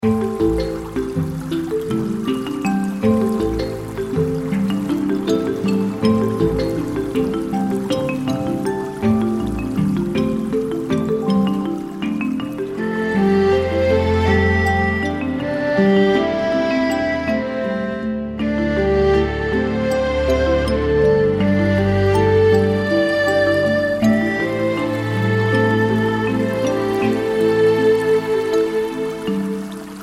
如水声叮咚作响，潺潺绵延不绝